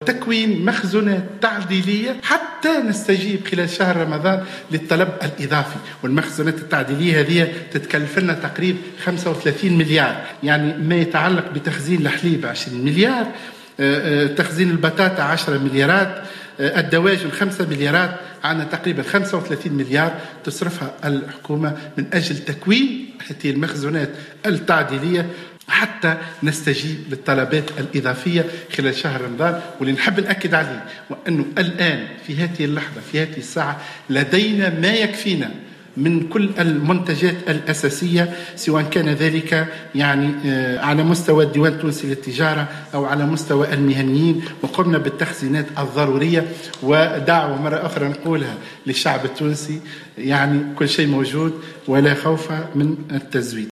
أكد وزير التجارة، محسن حسن اليوم خلال ندوة صحفية عقدتها الوزارة حول الاستعدادات لشهر رمضان أن جل المنتوجات ستكون متوفرة بالكميات الكافية خلال شهر رمضان وأنه لن يتم اللجوء إلى التوريد.